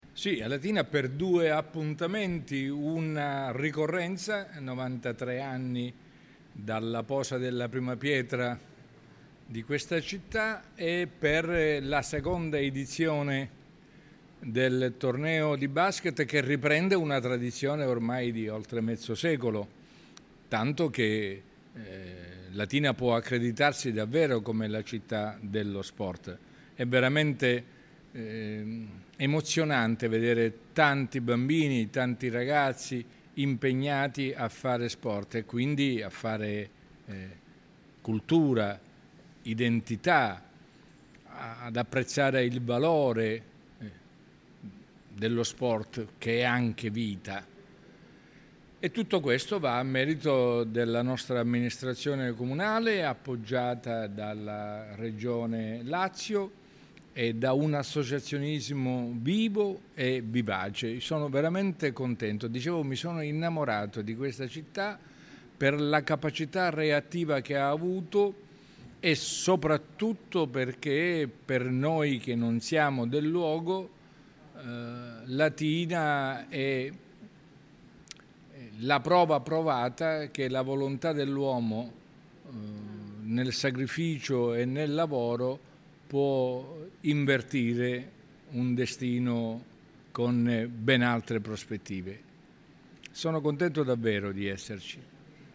«Mi sono innamorato di Latina», ha dichiarato Musumeci dal palco, sottolineando il valore simbolico e identitario di una città capace di rinascere dal nulla.
Le interviste
musumeci-basket.mp3